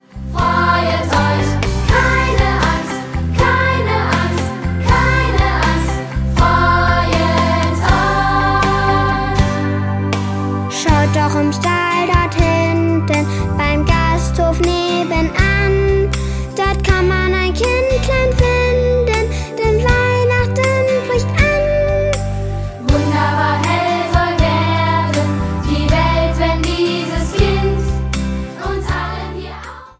Ein Weihnachts-Minimusical